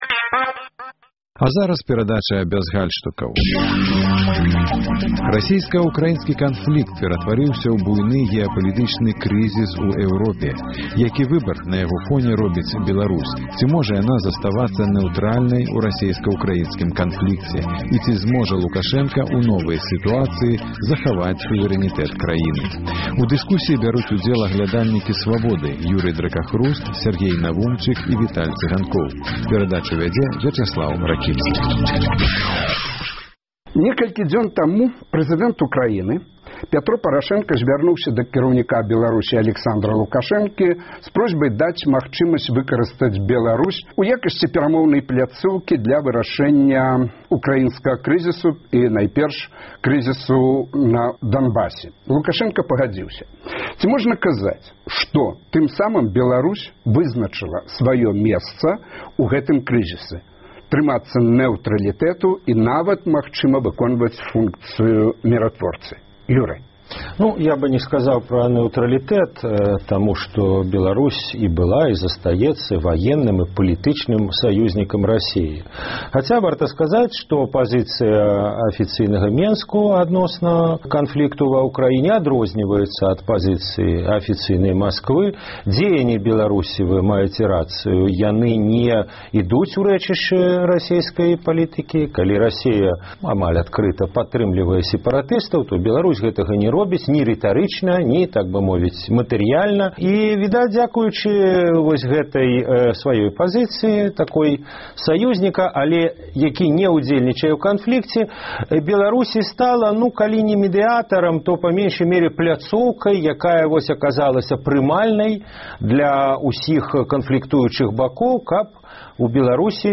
Абмеркаваньне гарачых тэмаў у студыі Свабоды.